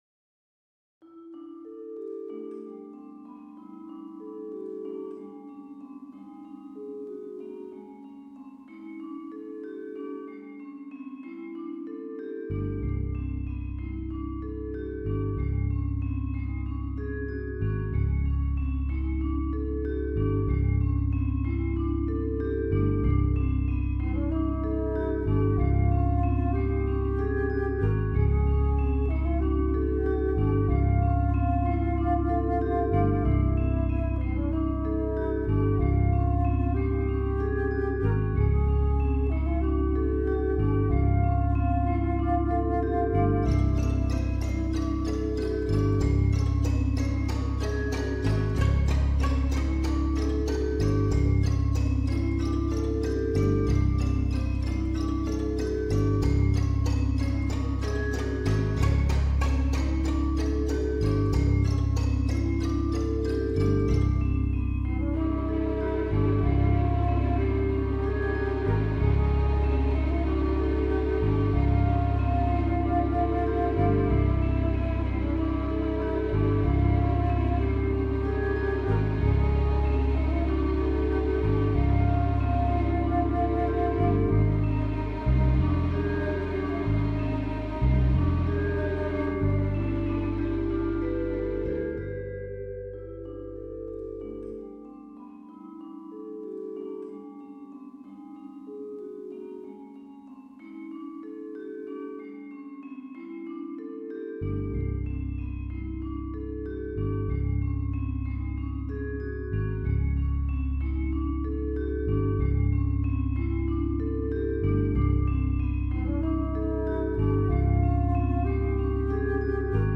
Underwater Cave - Microtonal VGM
This time I applied a microtonal inflection to the motif (in 24 TET). The environment would require the player to go cave-diving during this music.